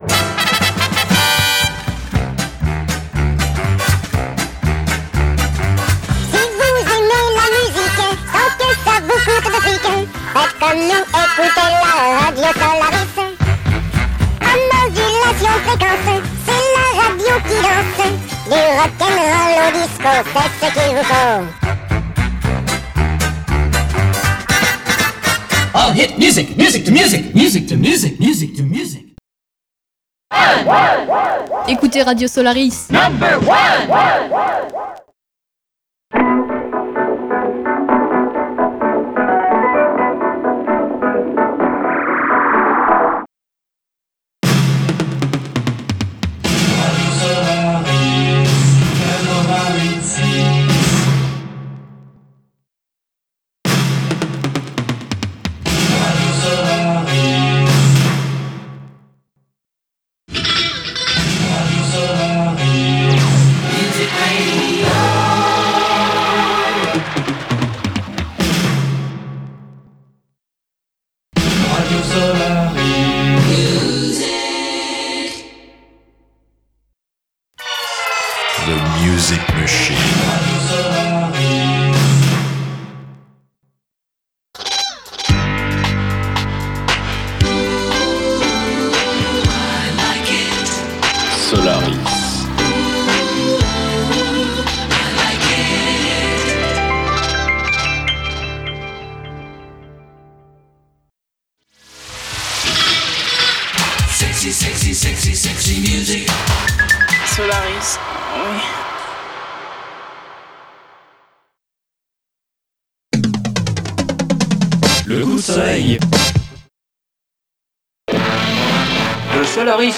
403 - Jingle informations depuis Paris [FM remix]